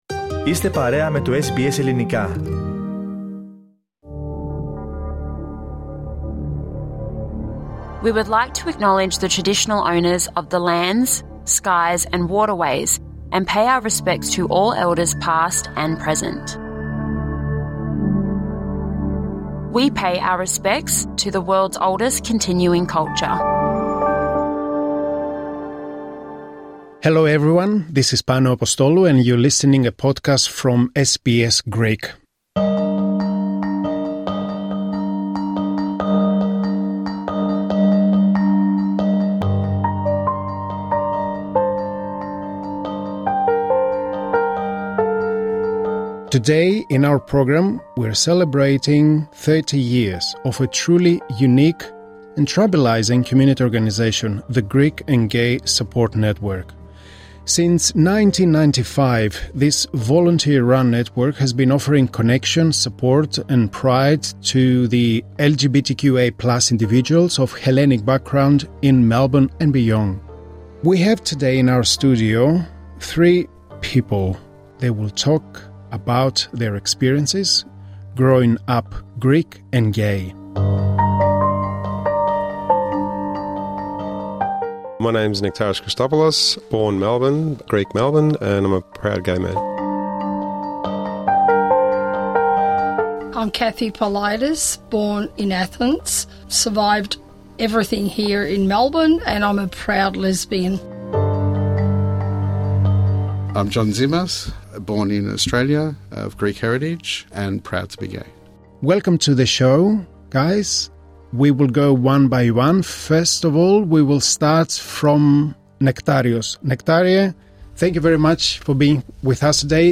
As the Greek and Gay Support Network (GGSN) celebrates its 30th anniversary this year, three of its longtime members, each with their own story of struggle and resilience, joined SBS Greek to reflect on what this milestone means for them, and for the wider community